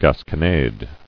[gas·con·ade]